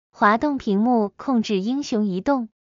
滑动屏幕.MP3